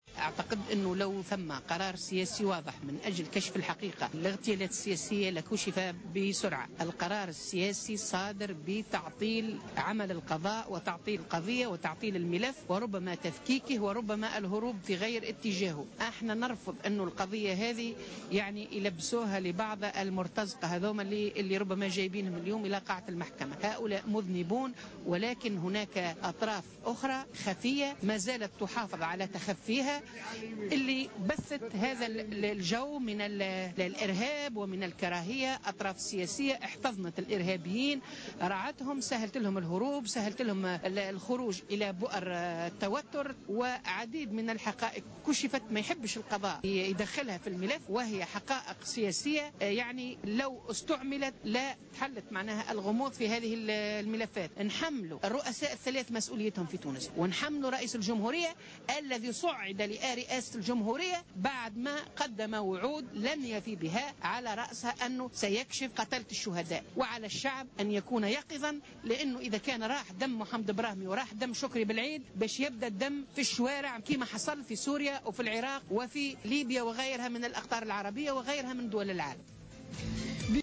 وأضافت في تصريح لمراسل "الجوهرة أف أم" على هامش وقفة احتجاجية نظمتها الجبهة الشعبية أمام مقر المحكمة الابتدائية بتونس للمطالبة بكشف الحقيقة في قضية اغتيال البراهمي أنه لا وجود لقرار سياسي واضح لمعرفة الحقيقة.